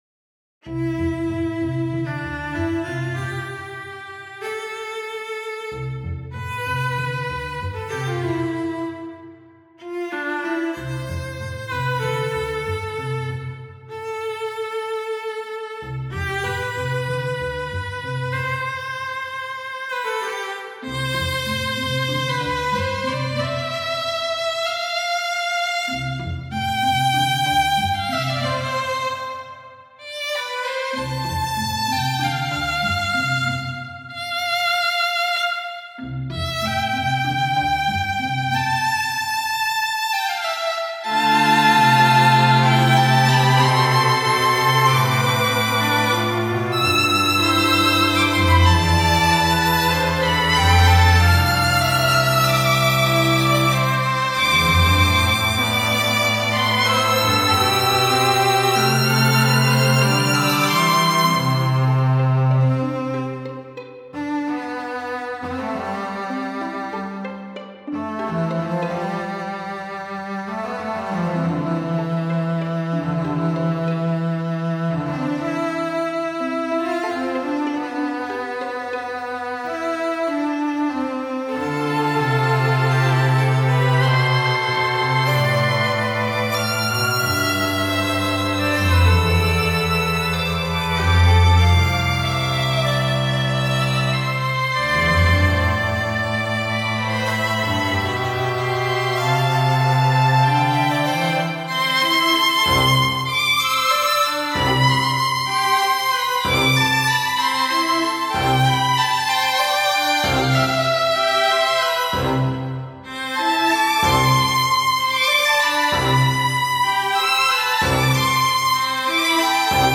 Andante Cantabile for string quartet